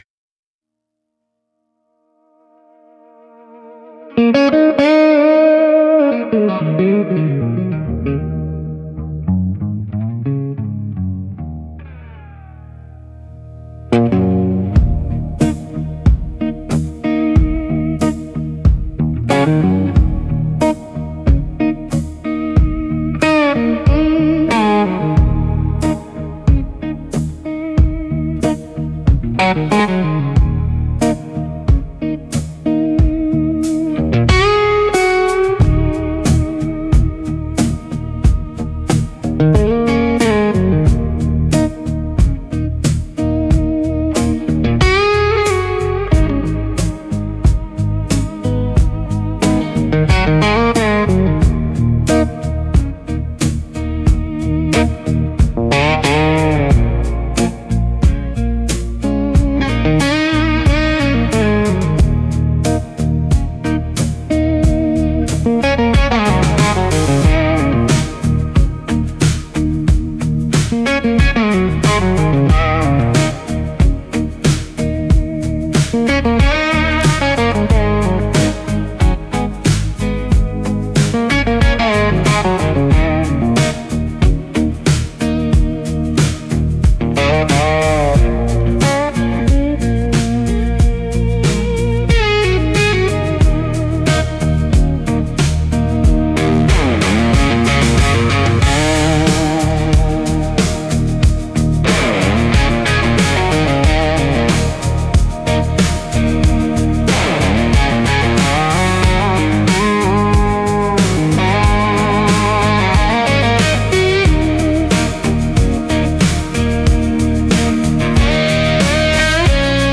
Instrumental - The Weathered Wood Groove